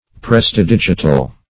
Search Result for " prestidigital" : The Collaborative International Dictionary of English v.0.48: Prestidigital \Pres`ti*dig"i*tal\, a. Nimble-fingered; having fingers fit for prestidigitation, or juggling.
prestidigital.mp3